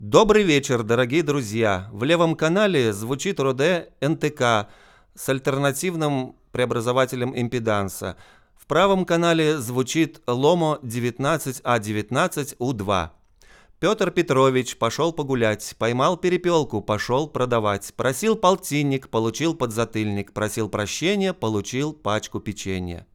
Запись с массой посторонего шума (записал только, что), в том числе с улицы но он особо не мешает. Это реальное звучание "голого" капсюля Rode NTK.